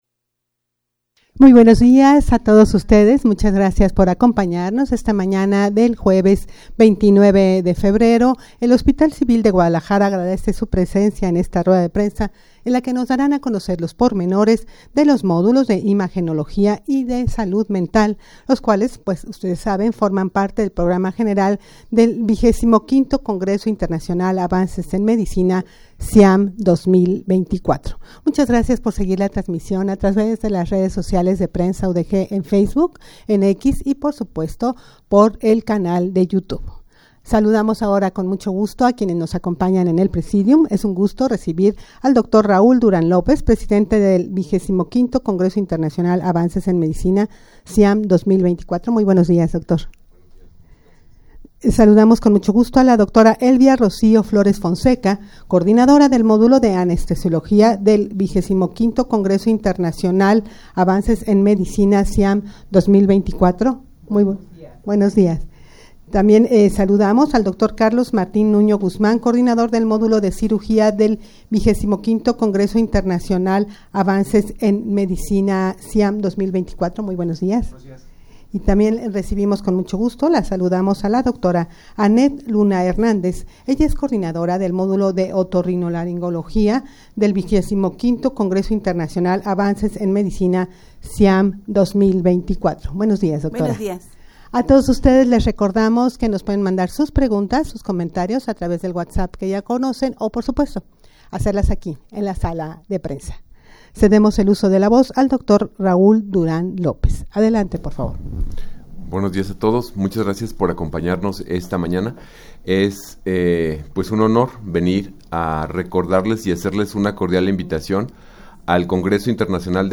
Audio de la Rueda de Prensa
rueda-de-prensa-para-dar-a-conocer-los-pormenores-de-los-modulos-de-anestesiologia-cirugia-y-de-otorrinolaringologia.mp3